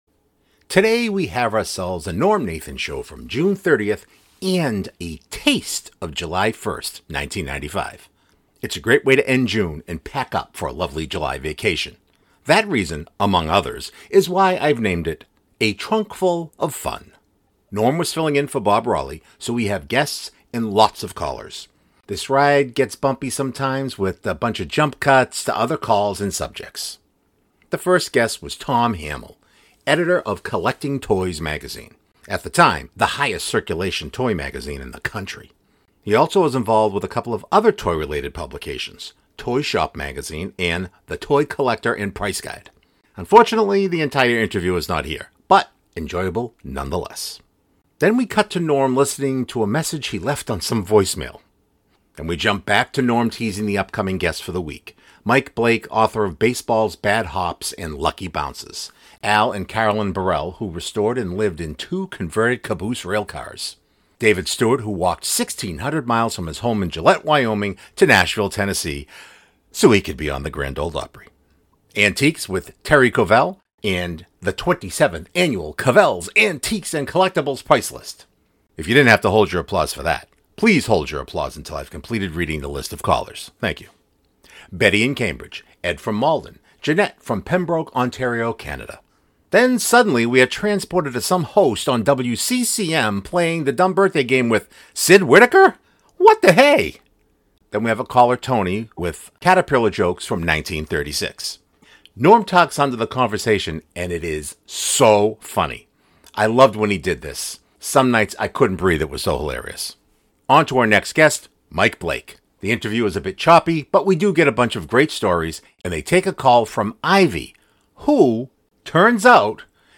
This ride gets bumpy with lots of jump cuts to other calls and subjects.
Unfortunately, the entire interview is not here but enjoyable, nonetheless.